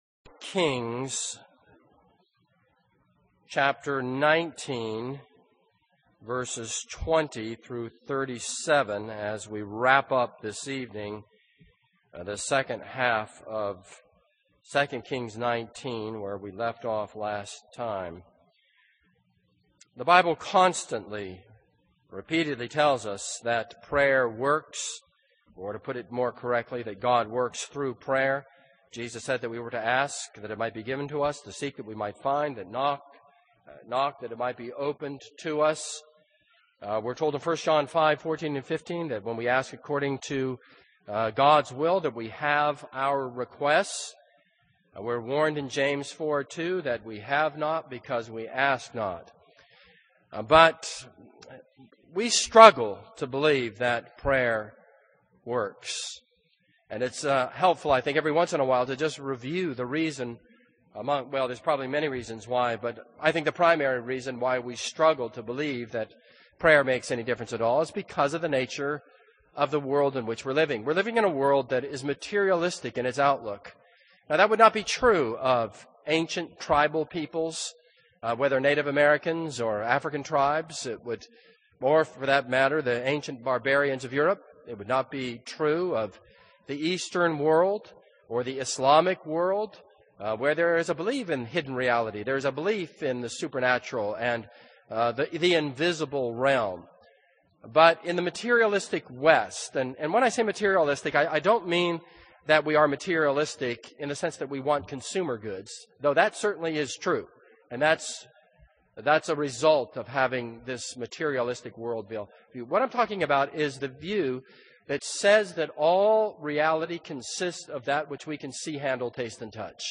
This is a sermon on 2 Kings 19:20-37.